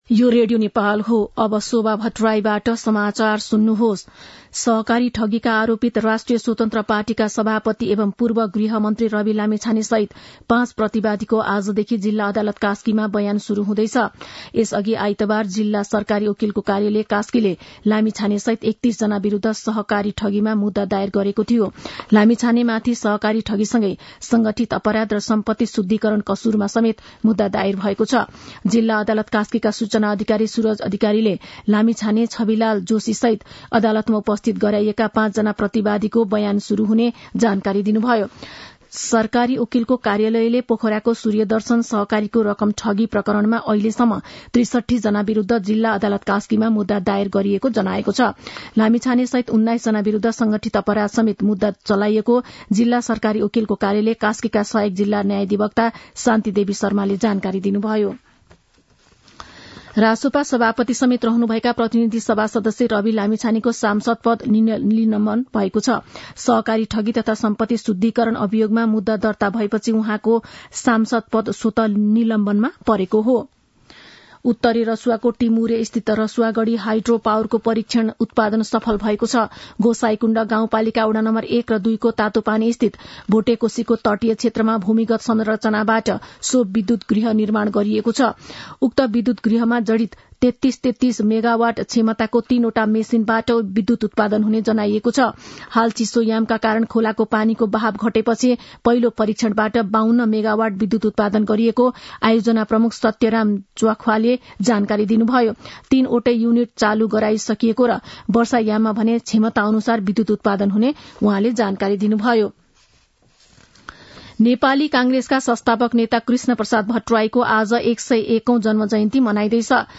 An online outlet of Nepal's national radio broadcaster
मध्यान्ह १२ बजेको नेपाली समाचार : ९ पुष , २०८१
12-am-nepali-news-1-17.mp3